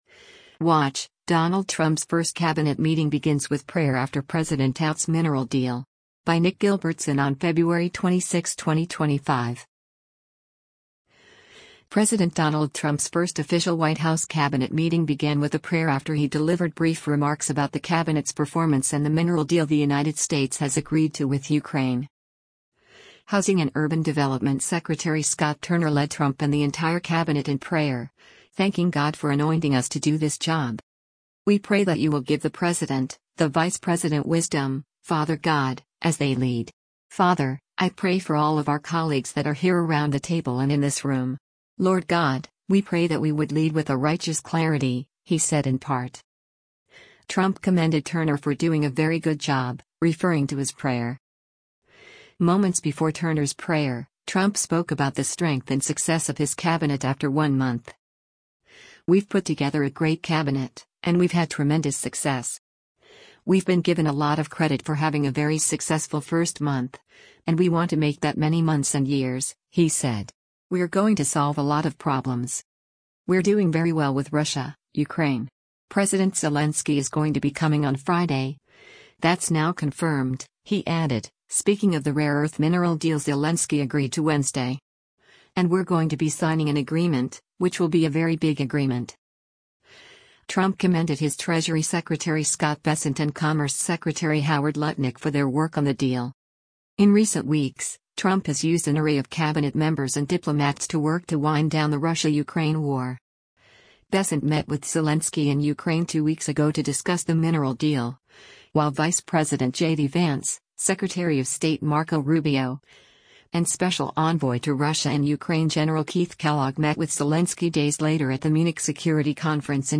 WATCH: Donald Trump’s First Cabinet Meeting Begins with Prayer After President Touts Mineral Deal
Housing and Urban Development Secretary Scott Turner led Trump and the entire cabinet in prayer, thanking God “for anointing us to do this job.”